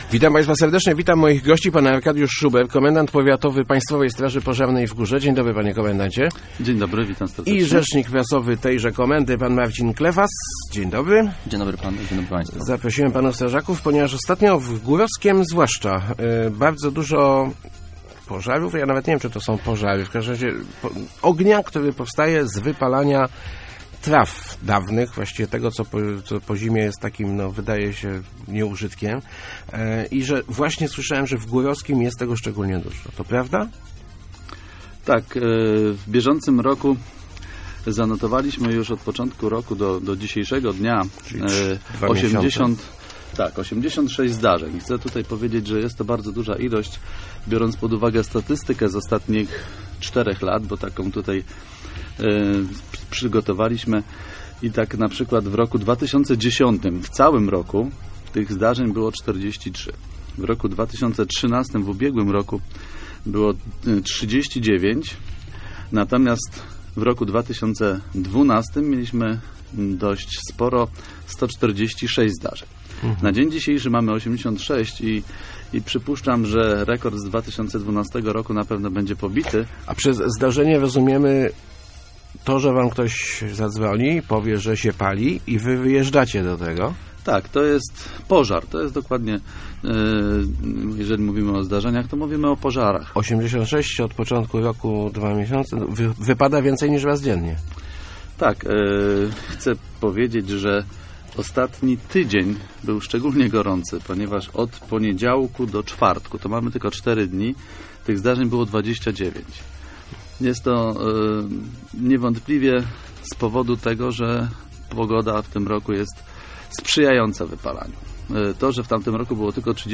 Wypalanie suchych traw to prawdziwa plaga w powiecie górowskim - mówili w Rozmowach Elki